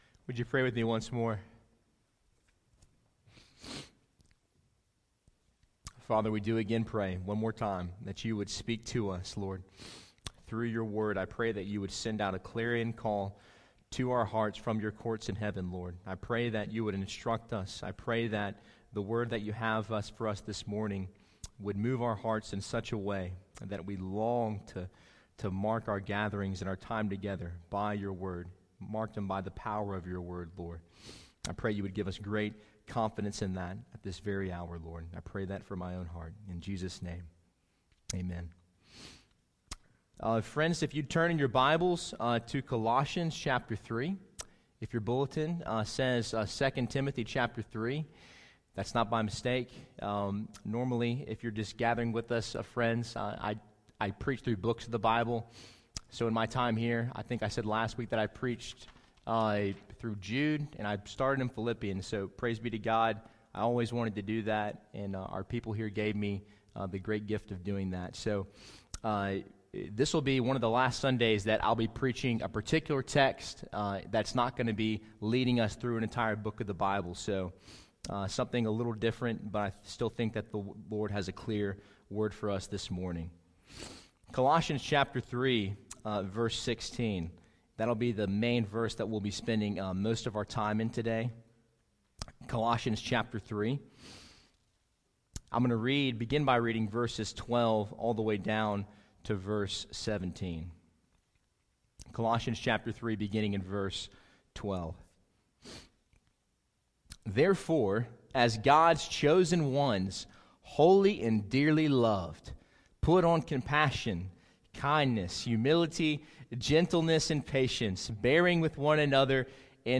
Sermon Audio 2017 December 31